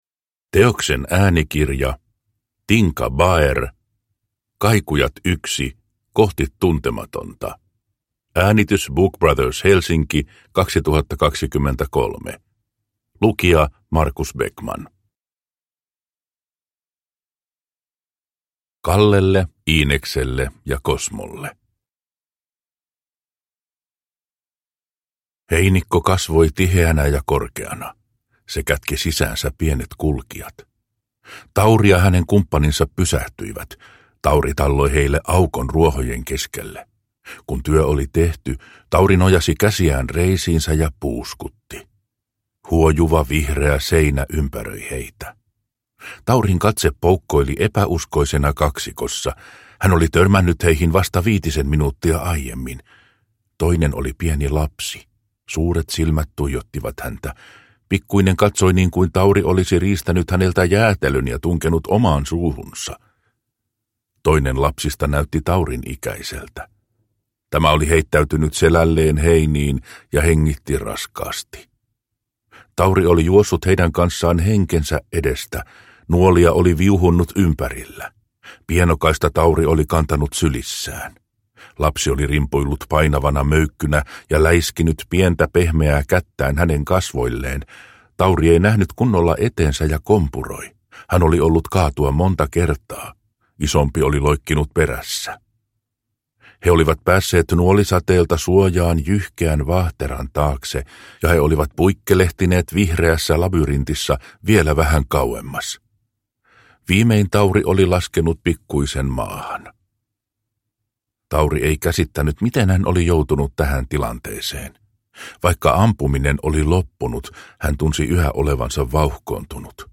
Kohti tuntematonta (Kaikujat 1) – Ljudbok – Laddas ner